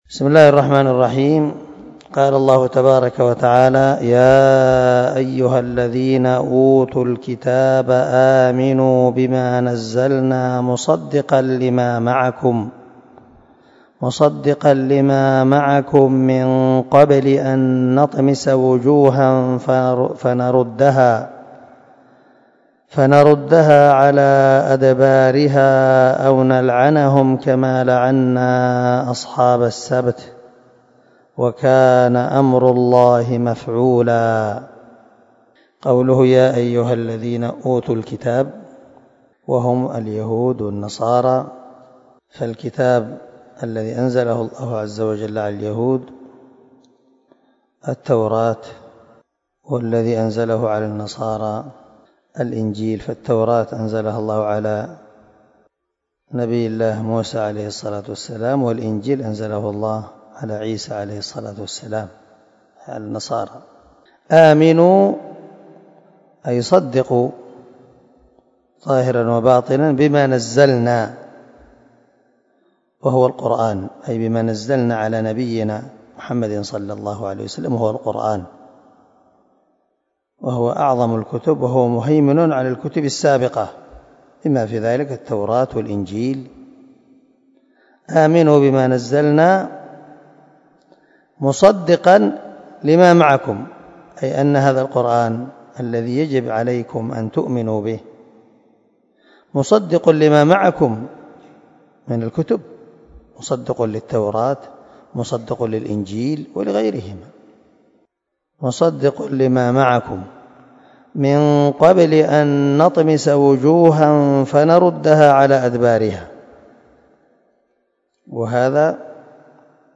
268الدرس 36 تفسير آية ( 47 ) من سورة النساء من تفسير القران الكريم مع قراءة لتفسير السعدي